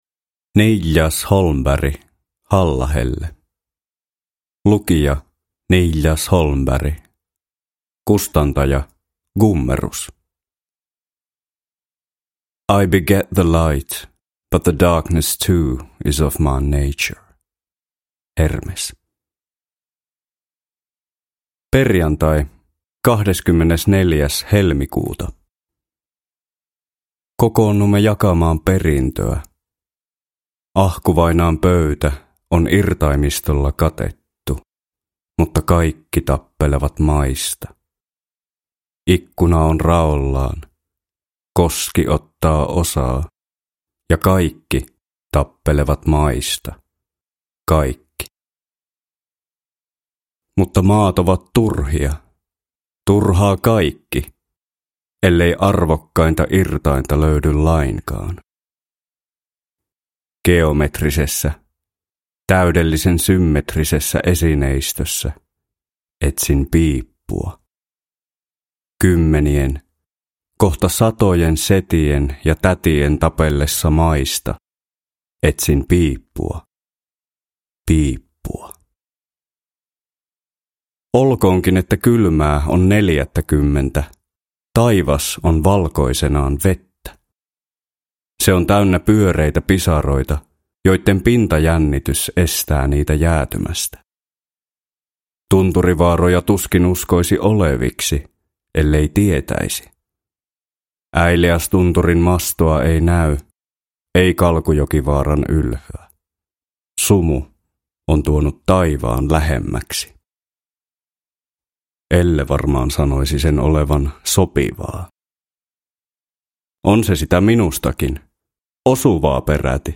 Halla Helle – Ljudbok – Laddas ner